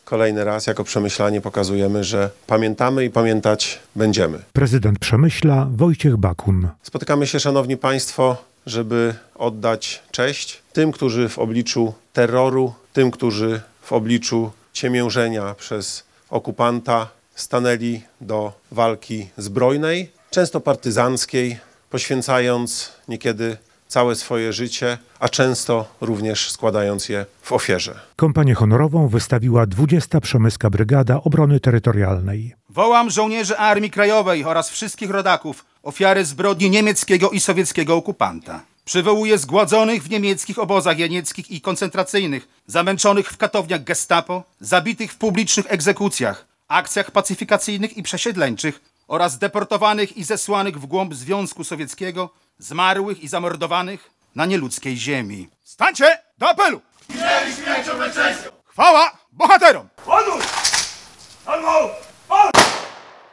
Wystąpienie Prezydenta Przemyśla
Podczas uroczystości na Cmentarzu Wojennym głos zabrał Prezydent Przemyśla, Wojciech Bakun: